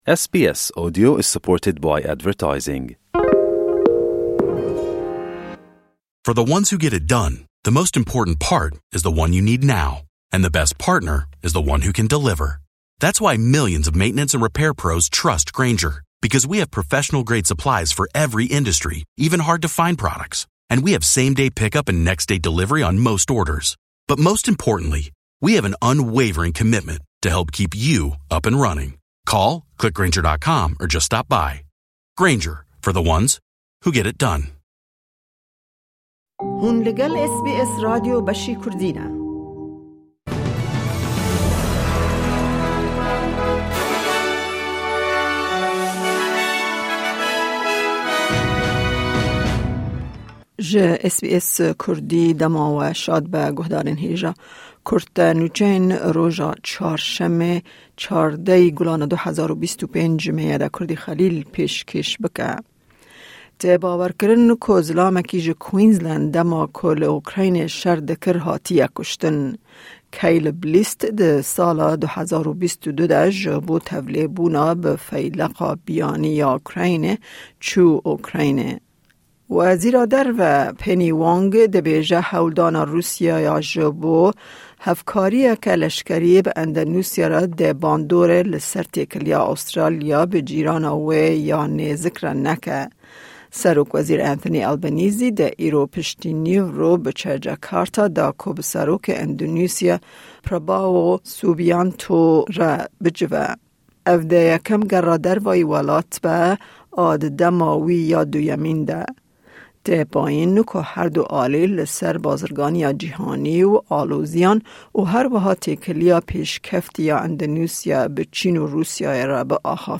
Kurte Nûçeyên roja Çarşemê, 14î Gulana 2025